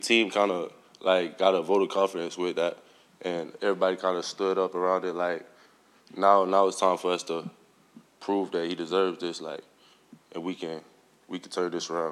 Despite injury setbacks, the Gators continue to strive for greatness and approach each week as an opportunity to get better, Napier said: